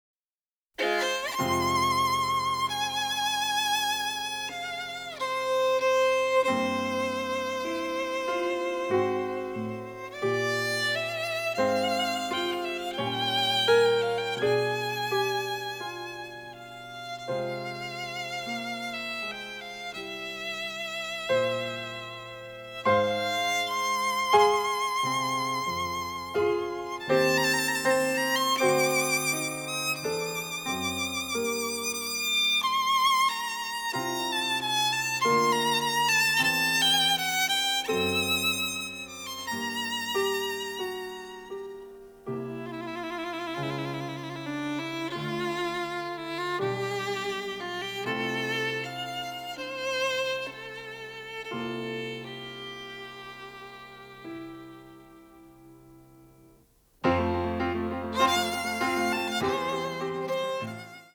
giallo score
sophisticated avant-garde sound